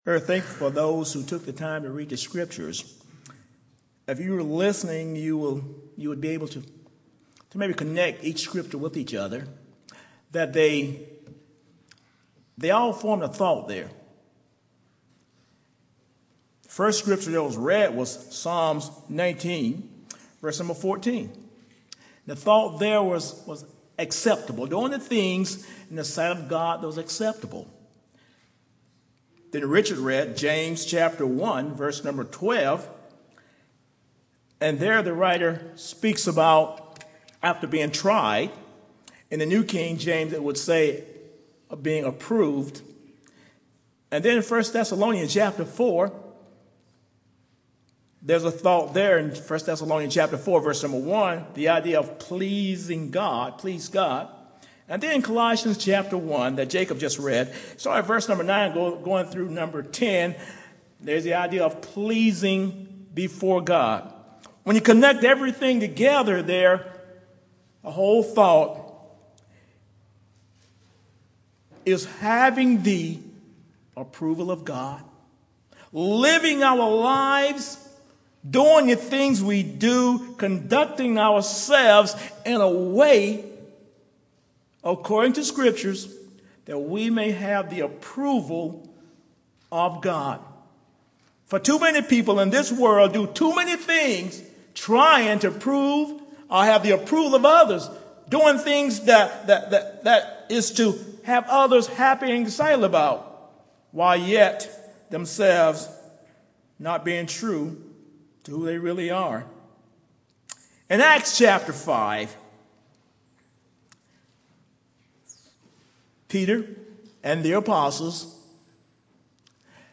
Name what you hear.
Categories: Sermons Tags: ,